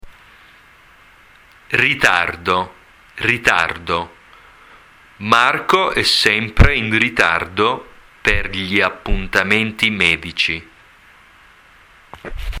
Click on the audio file to download and hear the pronunciation of the word and to hear it used in a sentence.